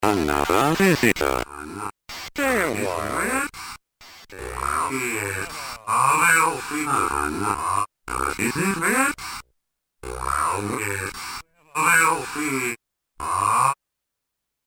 editSPEECH PROCESSED BY OTHER MACHINE I recorded some fun variations about the "another visitor" speech processed by different samplers, effects, vocoders or whatever!
Reverse Roland SDE3000
reverse2.mp3